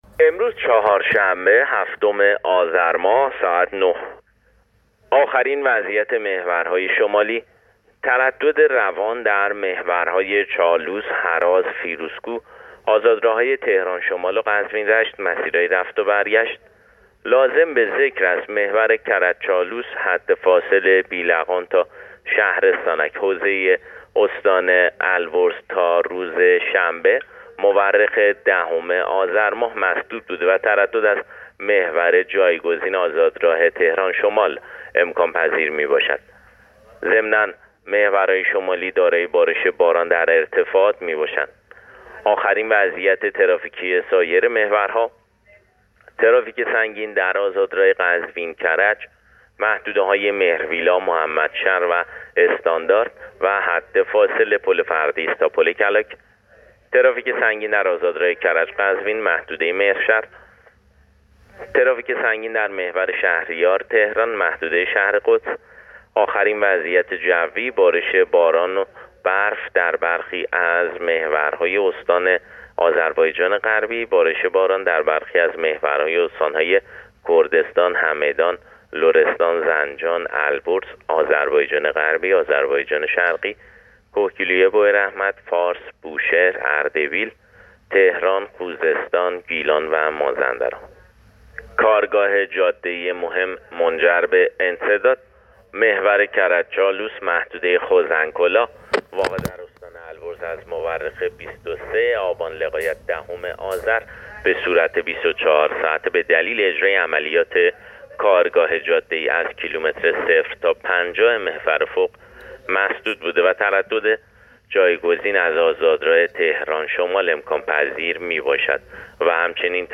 گزارش رادیو اینترنتی از آخرین وضعیت ترافیکی جاده‌ها تا ساعت ۹ هفتم آذر